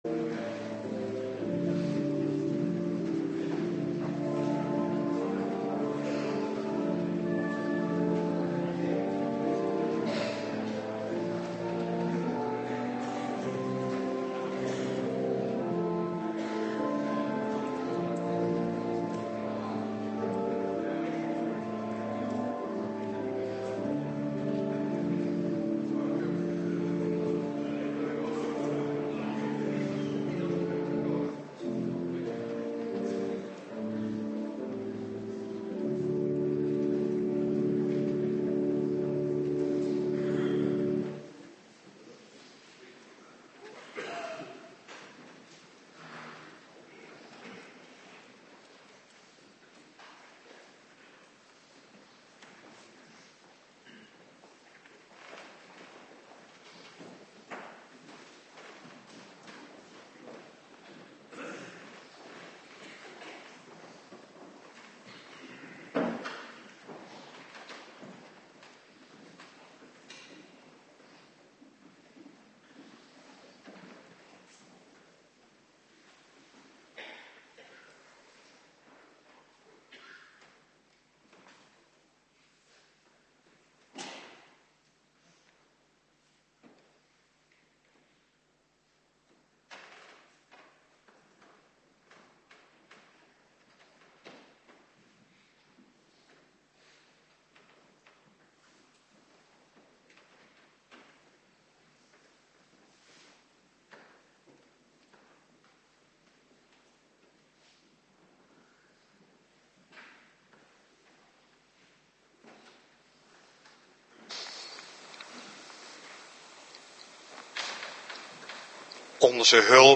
Avonddienst Voorbereiding Heilig Avondmaal
Locatie: Hervormde Gemeente Waarder